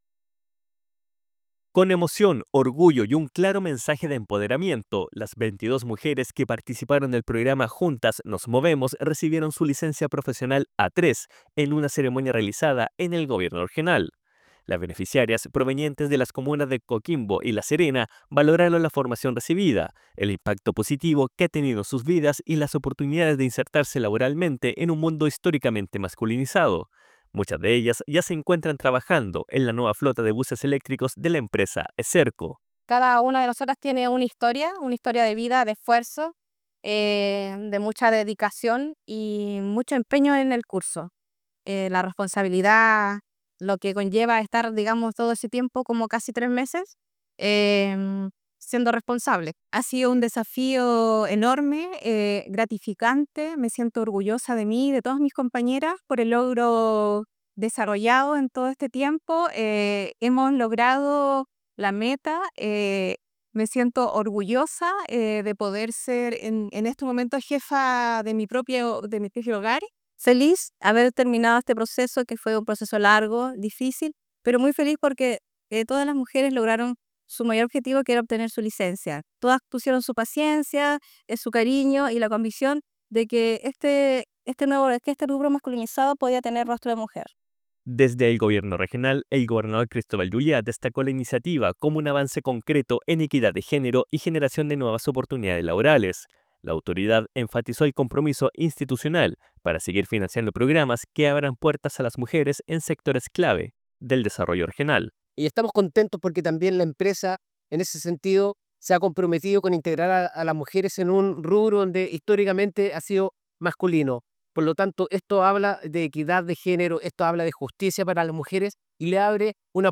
DESPACHO-RADIAL-CERTIFICACION-MUJERES-CONDUCTORAS-BUSES-ELECTRICOS-GORECOQUIMBO-.mp3